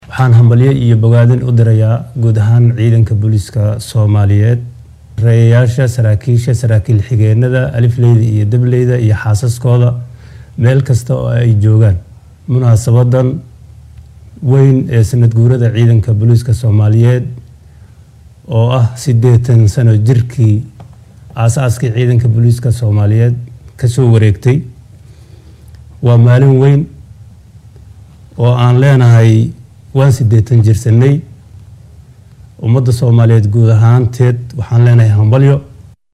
Taliyaha ciidanka Boliiska Soomaaliyeed General Sulub Axmad Firin ayaa bulshada la wadaagay hambalyo ku aaddan sanadguuradii 80-aad ee ka soo wareegatay xilligii la aasaasay ciidanka booliiska Soomaaliya.